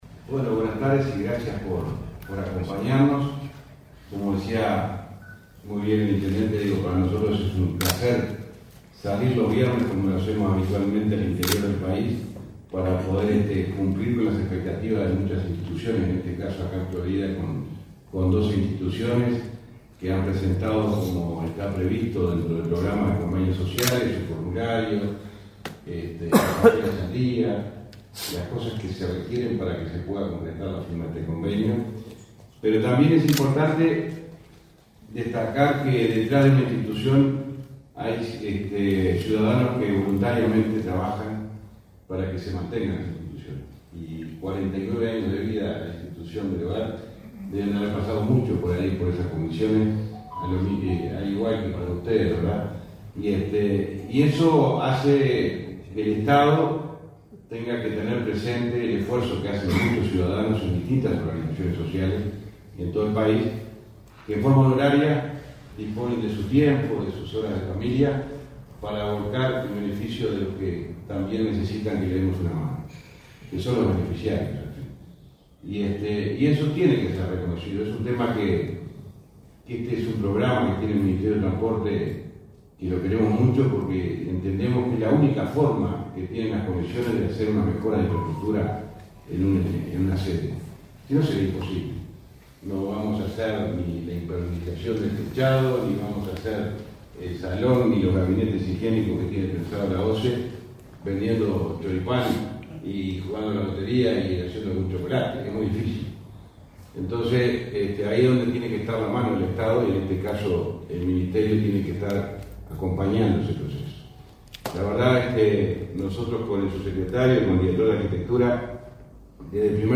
Palabras del ministro de Transporte y Obras Públicas, José Luis Falero
Este 21 de julio, el ministro de Transporte y Obras Públicas, José Luis Falero, participó en la firma de convenios sociales con el Club Social y